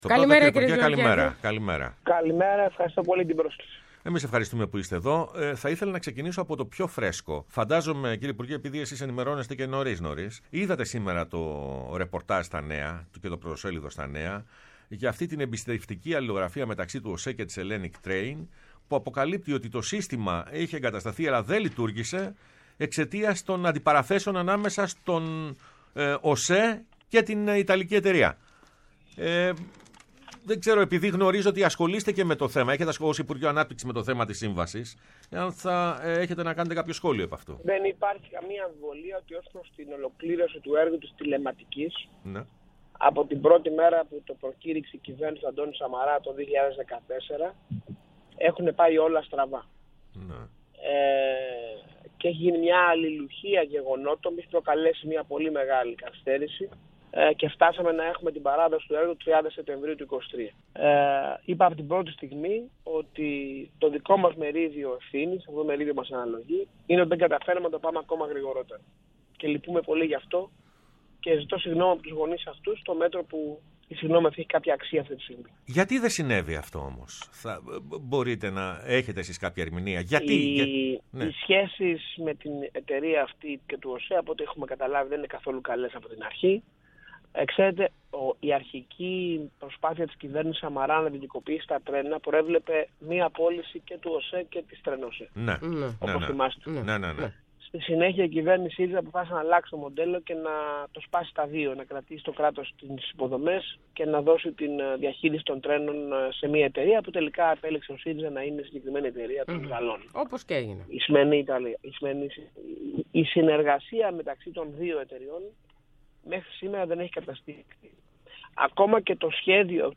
Ο Υπουργός Ανάπτυξης Άδωνις Γεωργιάδης στο Πρώτο Πρόγραμμα | 06.03.23